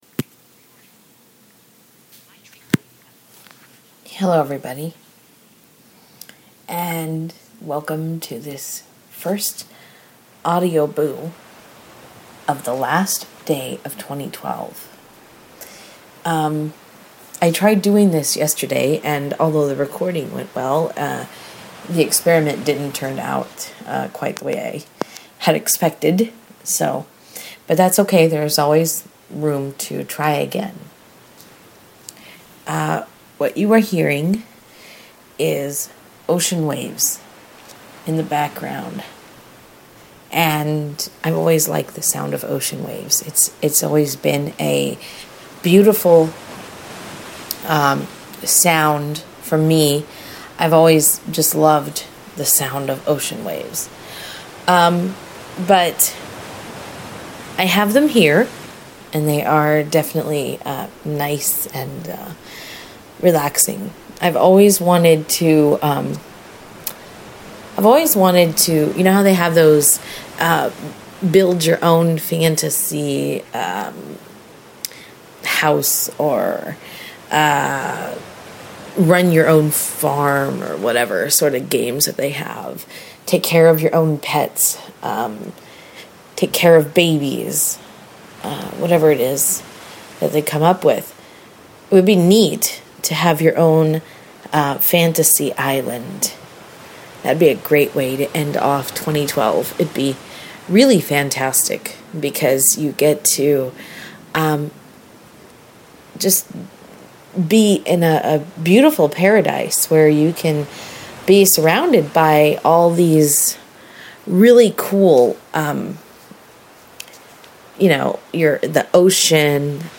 New Years Eve boo with ocean waves in the background